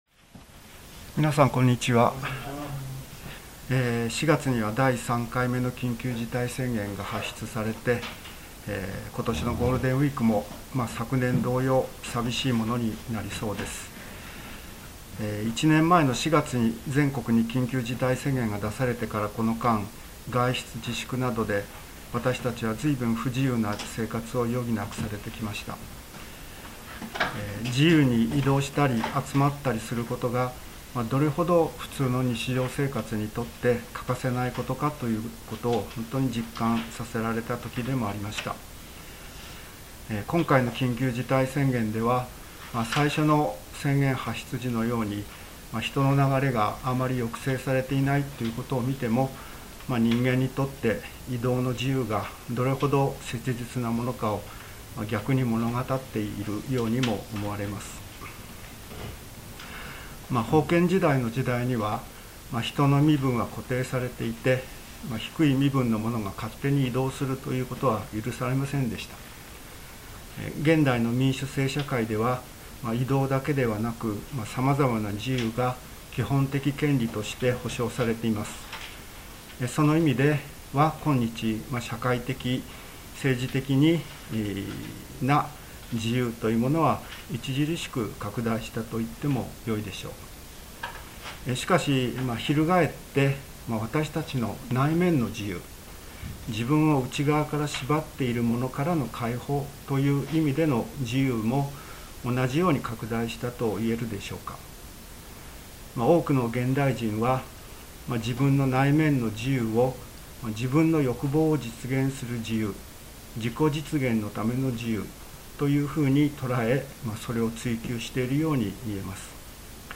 聖書メッセージ キリストにある罪の赦しと自由